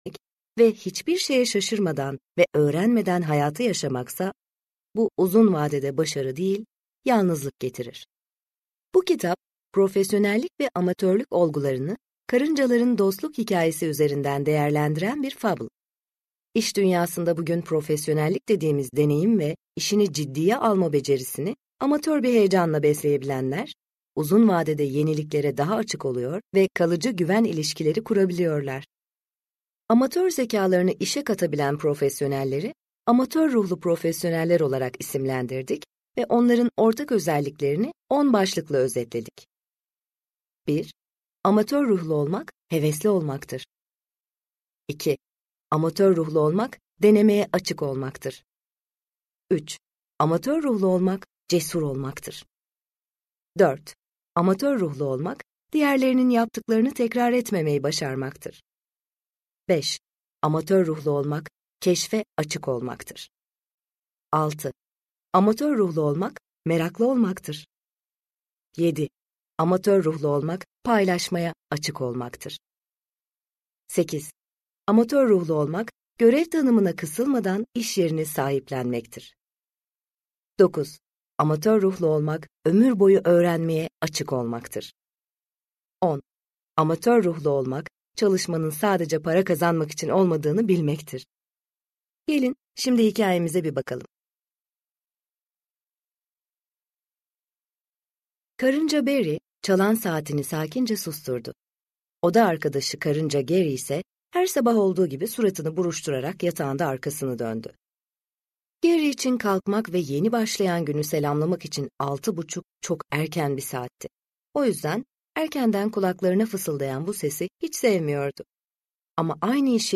Kaşif Karınca - Seslenen Kitap